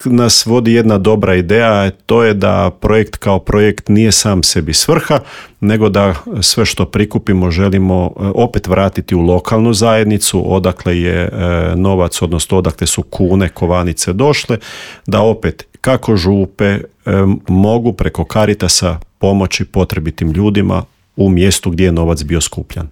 O projektu smo u Intervjuu MS-a razgovarali